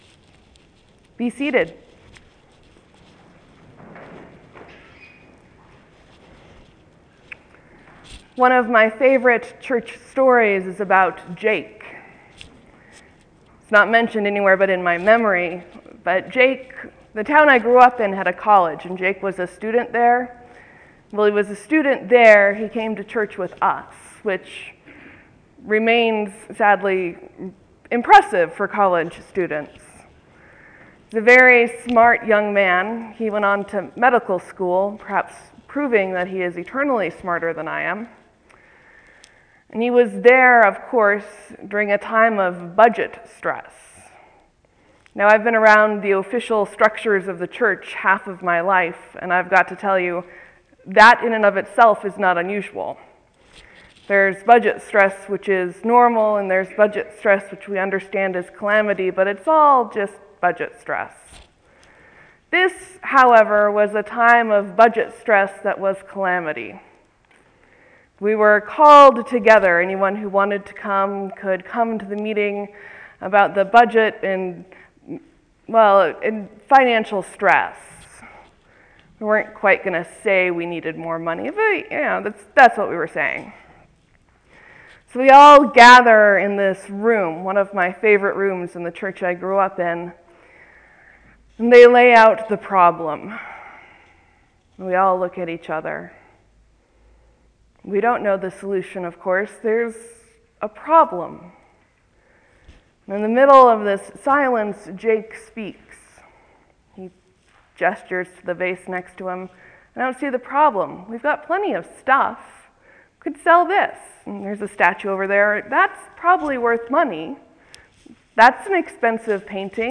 Things that Last, a sermon for 17 Nov 2013